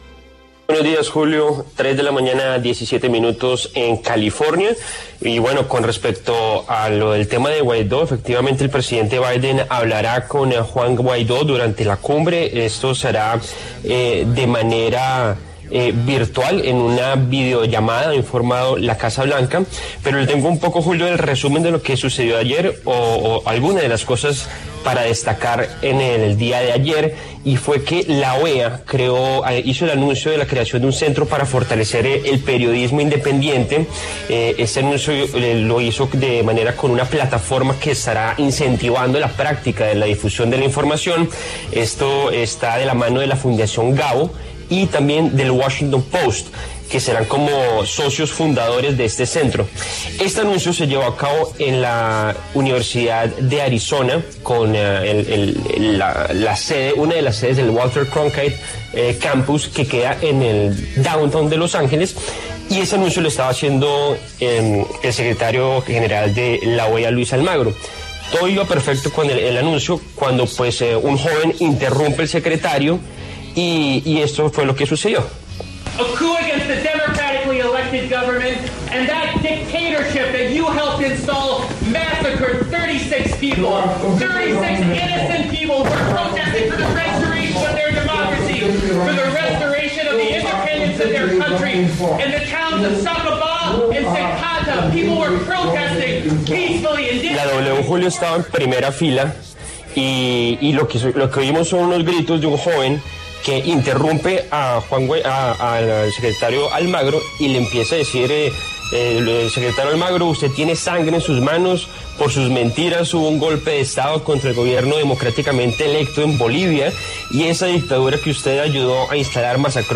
Un joven interrumpió a gritos a Luis Almagro para culparle del “golpe” en Bolivia
El hecho ocurrió durante una charla del secretario general de la OEA, Luis Almagro, sobre la libertad de prensa en el continente americano, celebrada con motivo de la IX Cumbre de las Américas.